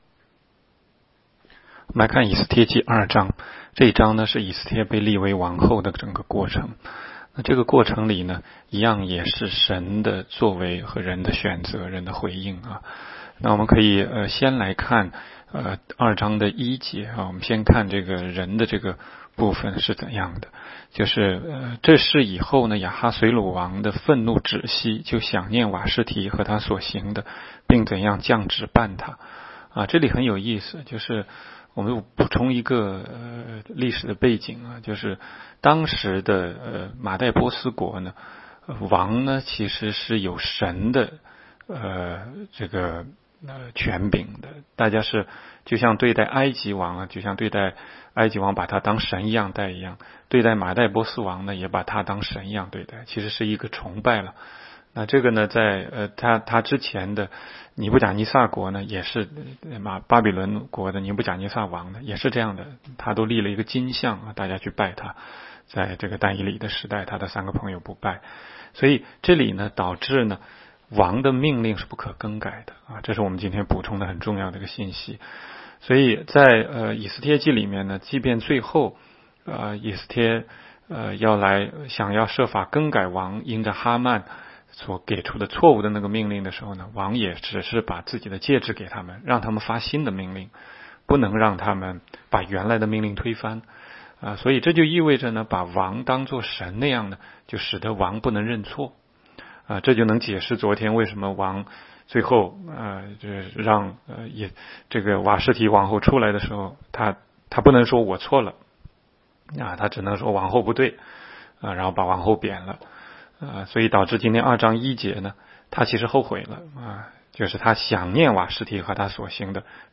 16街讲道录音 - 每日读经-《以斯帖记》2章